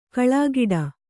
♪ kaḷāgiḍa